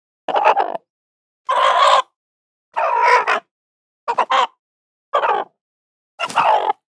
Sound Effects
Chicken Screams Nervous Sound